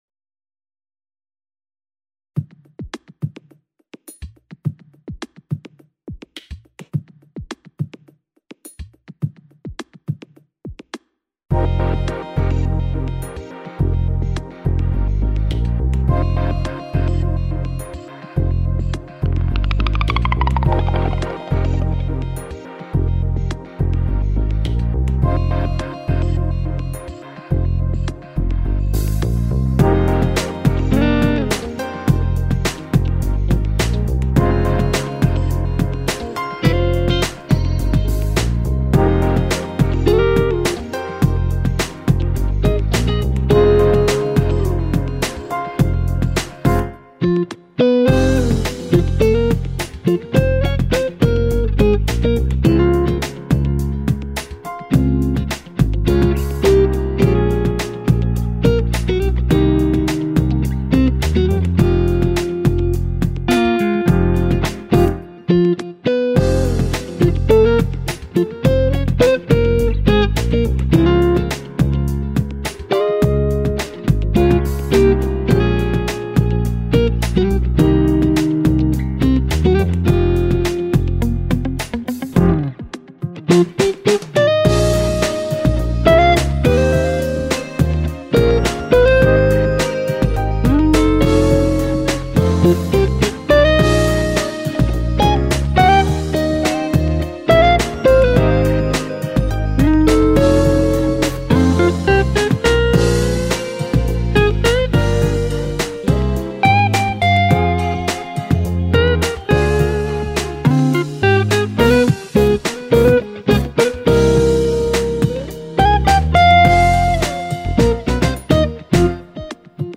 有點南方爵士風味
第二屆 "當代吉他創作大賽" 前三名